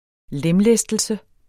Udtale [ ˈlεmˌlεsdəlsə ]